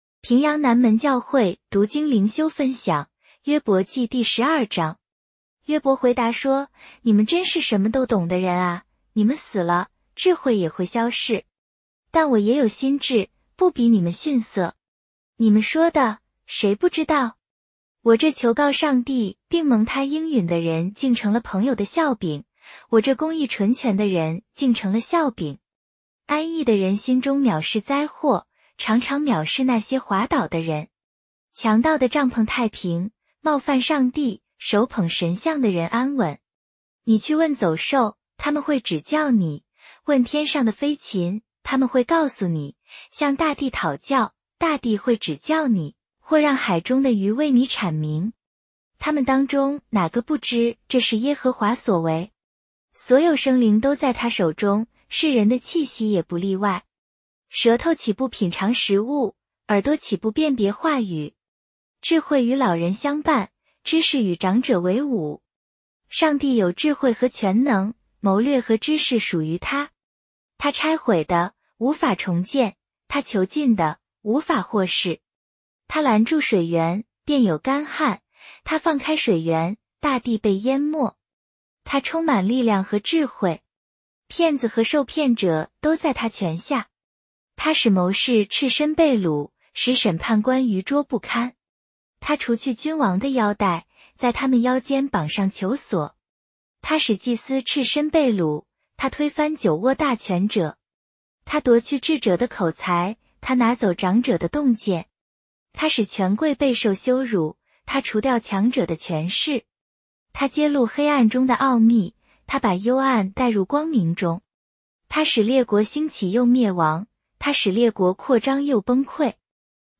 普通话朗读——伯12